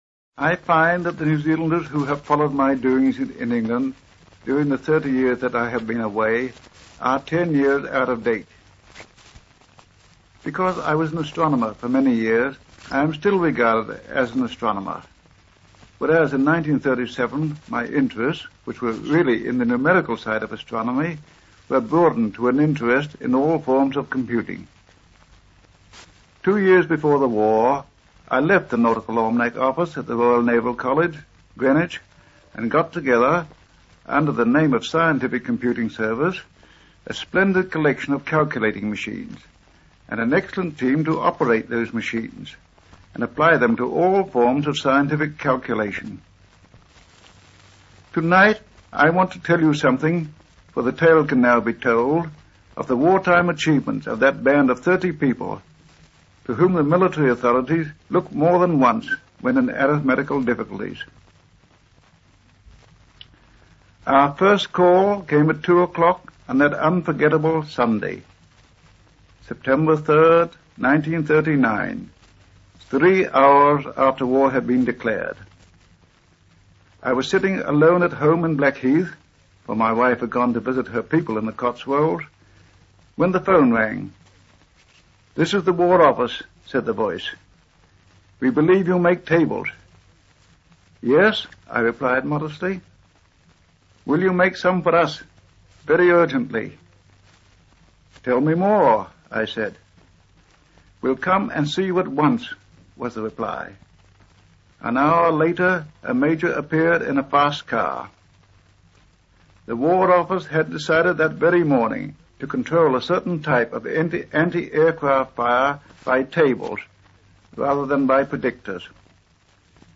Aufnahme eines Interviews mit Comrie mit dem Titel „Mathematik im Krieg“ (in englischer Sprache).